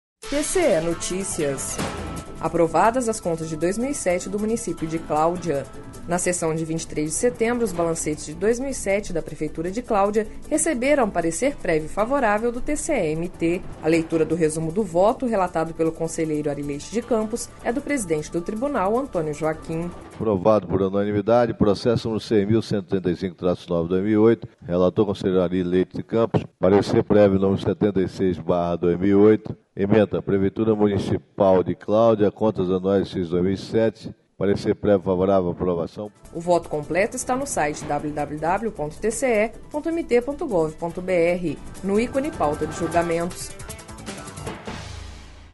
Na sessão de 23 de setembro, os balancetes de 2007 da prefeitura de Cláudia receberam parecer prévio do TCE-MT./ A leitura do resumo do voto, relatado pelo conselheiro Ary Leite de Campos, é do presidente do Tribunal, Antonio Joaquim.